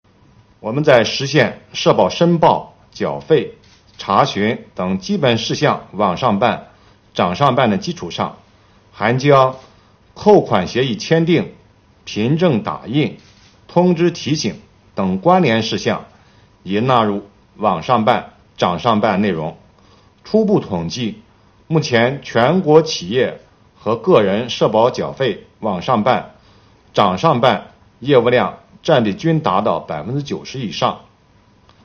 7月26日，国家税务总局召开新闻发布会，就税收大数据反映经济发展情况、税务部门学党史办实事扎实推进办税缴费便利化、打击涉税违法犯罪等内容进行发布并回答记者提问。会上，国家税务总局社会保险费司（非税收入司）副司长王发运介绍了目前社保缴费“网上办”“掌上办”的进展以及税务部门为推动社保缴费便利化所采取的措施。